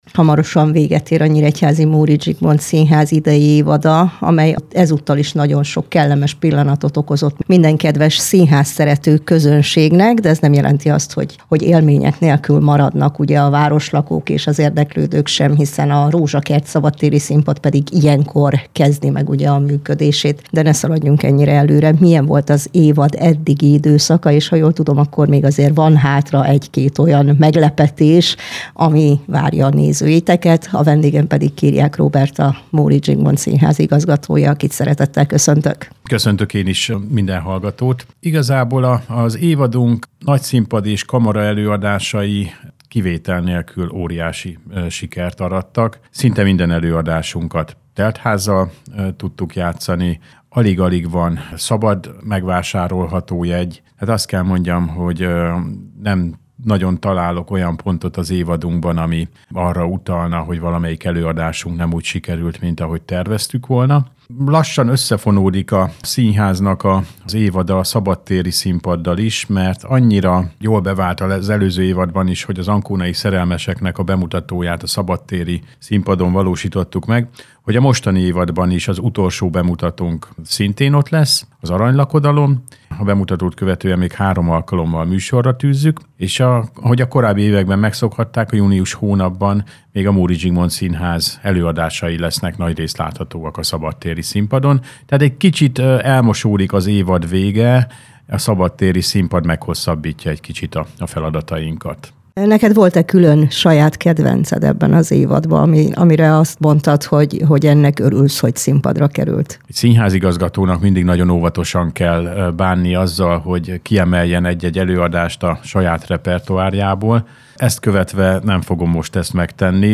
Beszélgetés
A beszélgetés május 4-én, vasárnap 13 órától volt hallható a Mozaik című magazinműsorban.